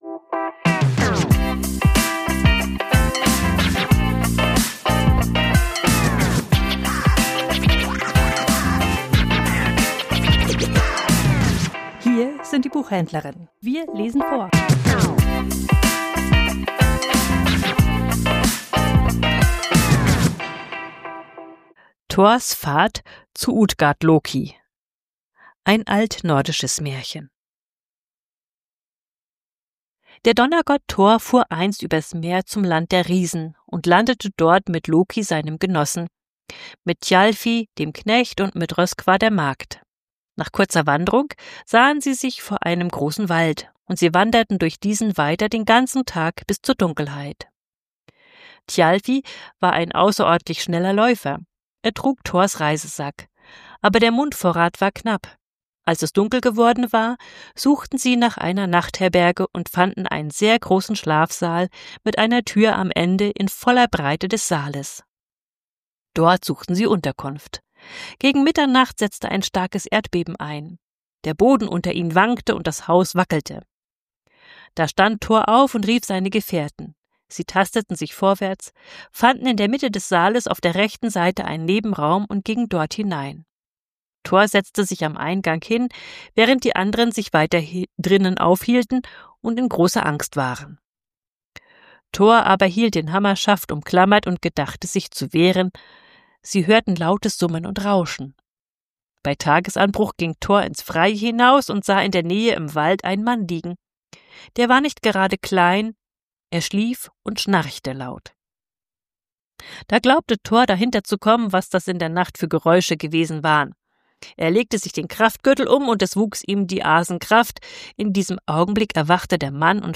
Vorgelesen: Thors Fahrt zu Utgard Loki ~ Die Buchhändlerinnen Podcast